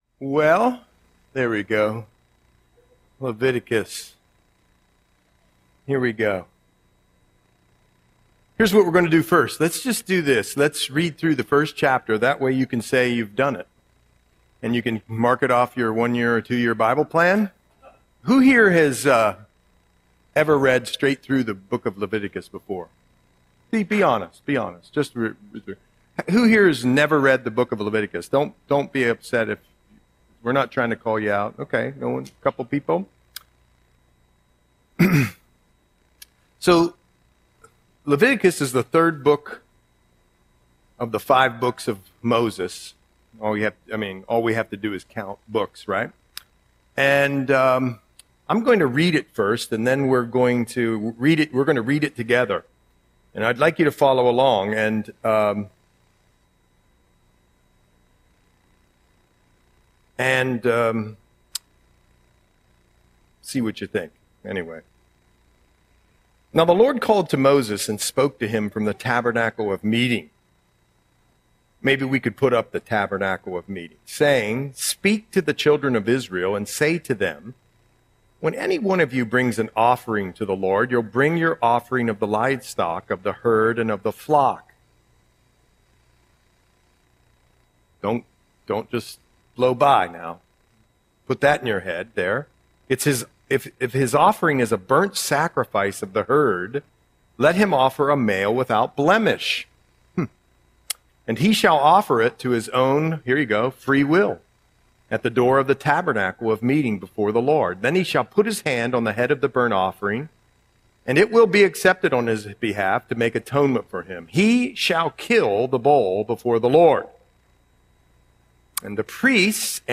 Audio Sermon - August 27, 2025